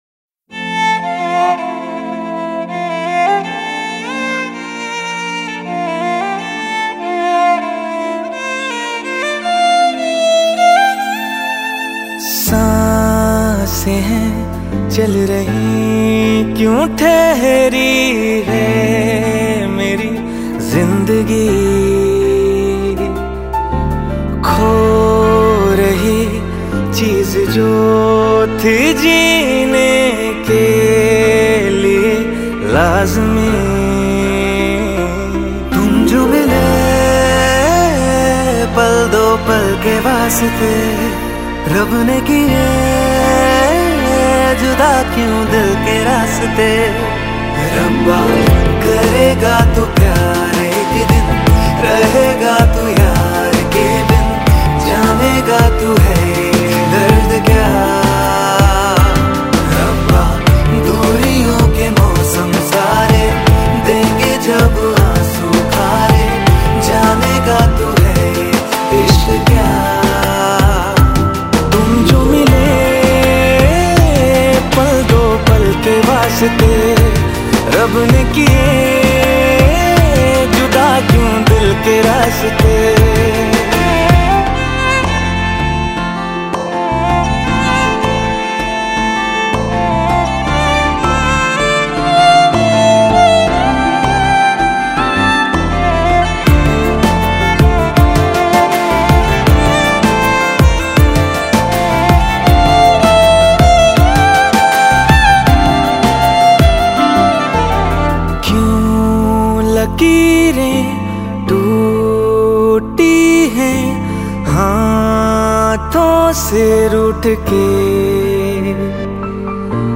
Bollywood Mp3 Music 2016